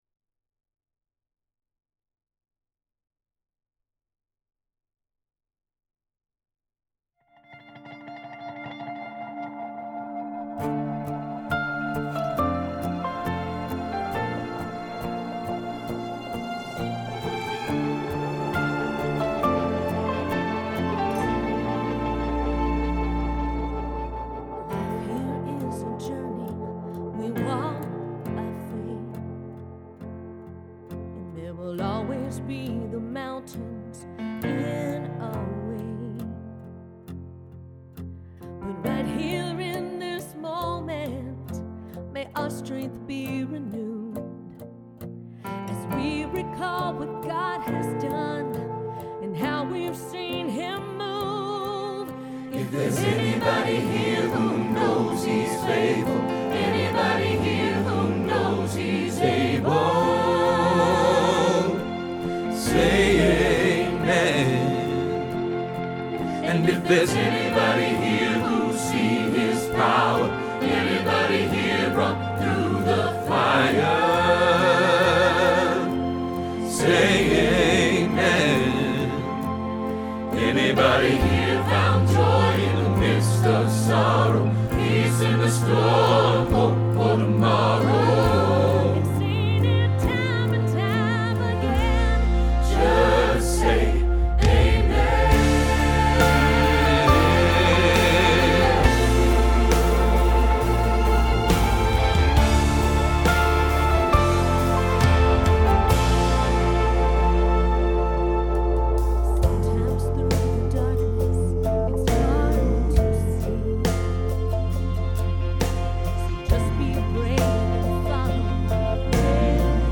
Say Amen – Bass – Hilltop Choir